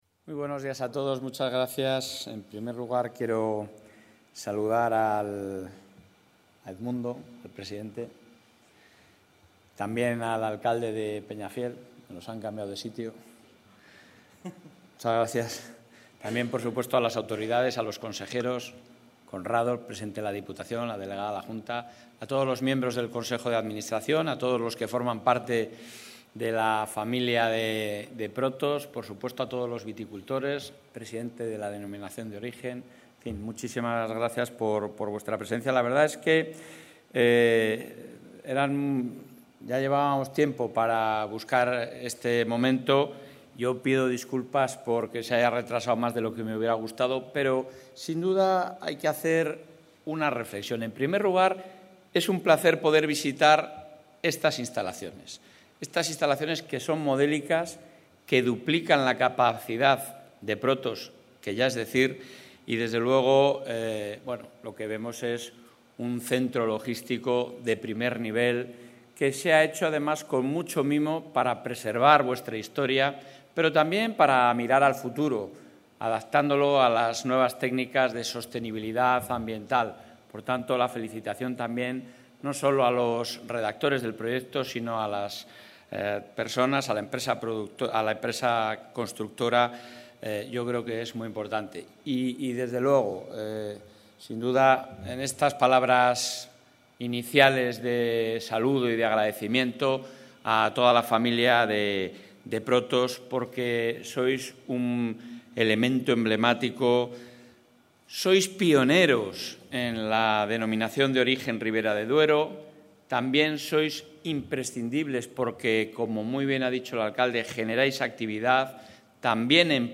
Intervención del presidente de la Junta.